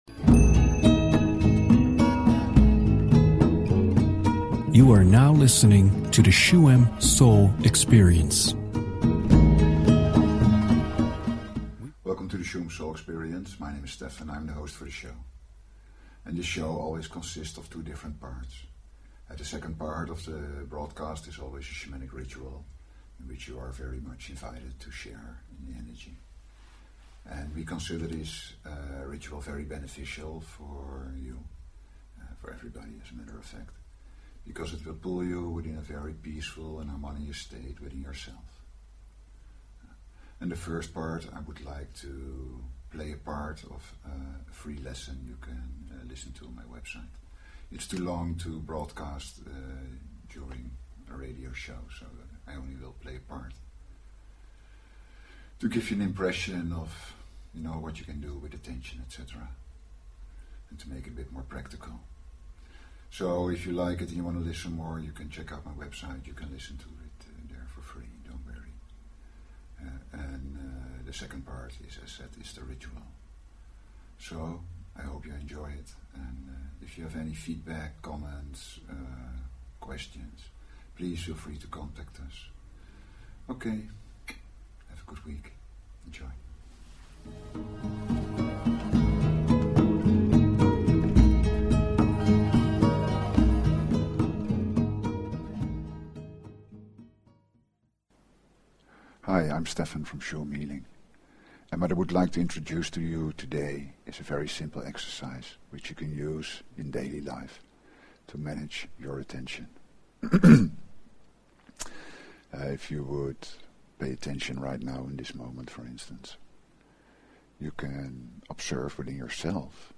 Talk Show Episode, Audio Podcast, Shuem_Soul_Experience and Courtesy of BBS Radio on , show guests , about , categorized as
Shuem Soul Experience is a radio show with: